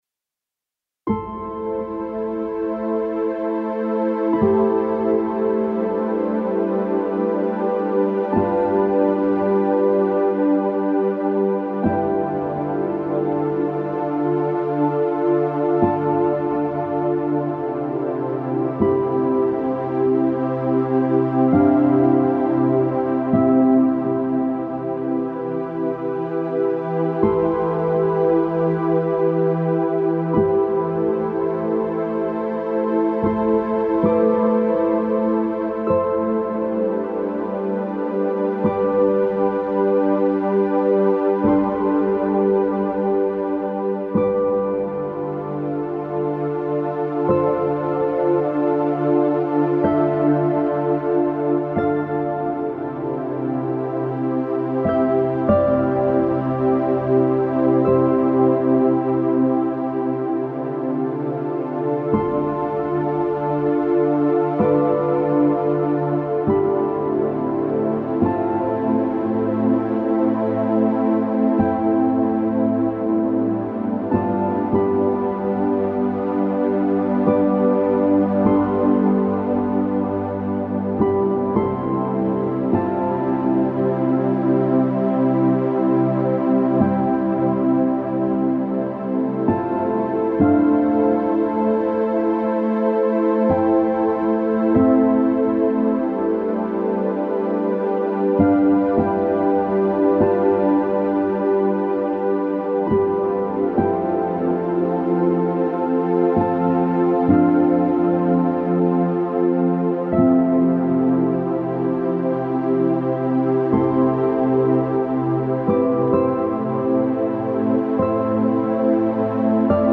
Largo [0-10] - - nappes - aerien - aquatique - drones - ciel